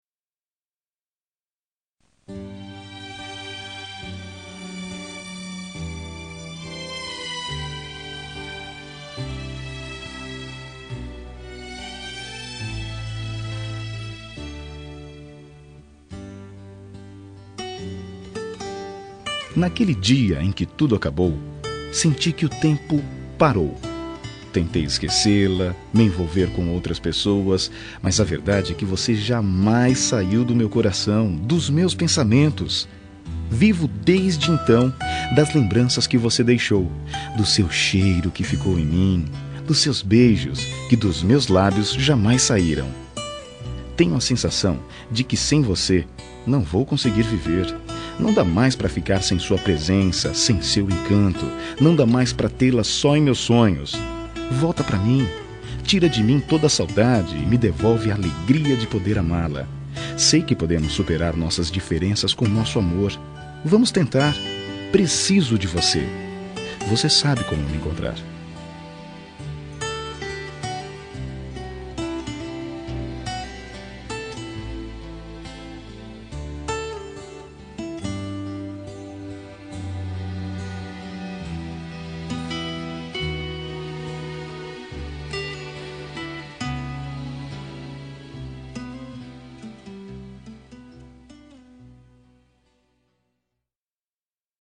Telemensagem de Reconciliação Romântica – Voz Masculina – Cód: 908